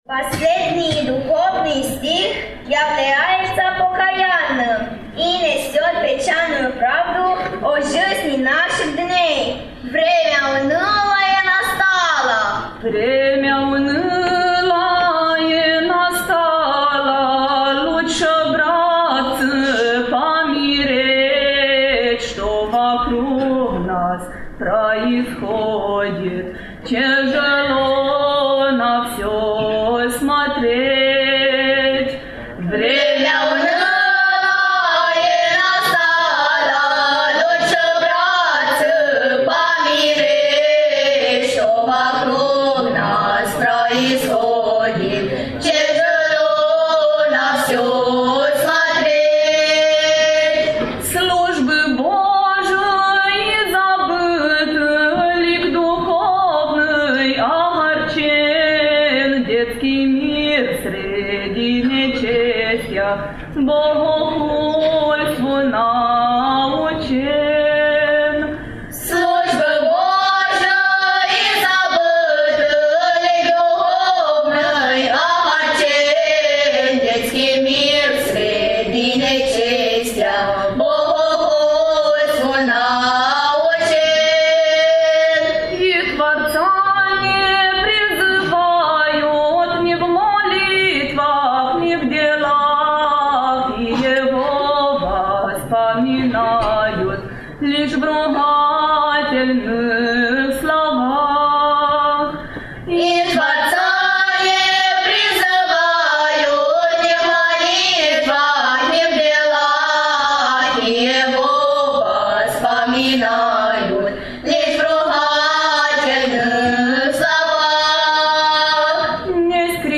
Înspre finalul dialogului nostru ne amintește și de Corul de Copii din Galați, cor pe care-l audiem în ediția de astăzi a emisiunii noastre cu câteva stihuri duhovnicești interpretate în cadrul festivalului.
CRL-Galati_Stih-duhovnicesc_Festival-2019.mp3